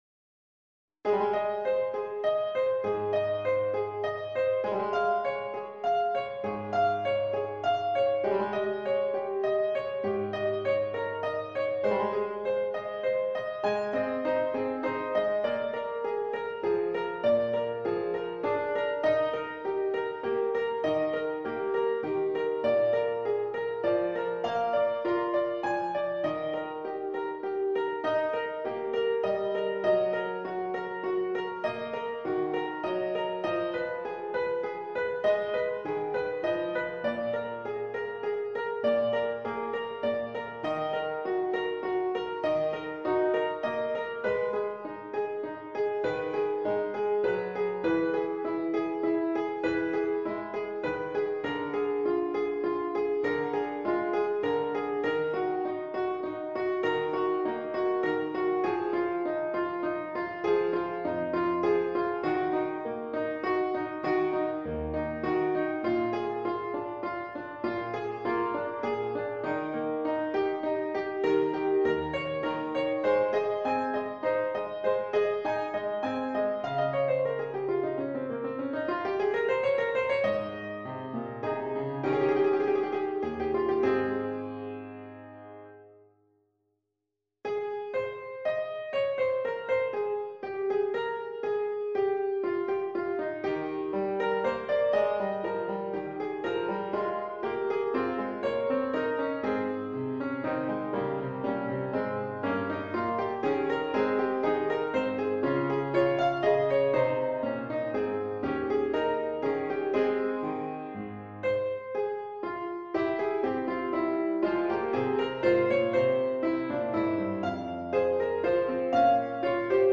一手小小的钢琴曲
降A大调前奏曲与赋格.mp3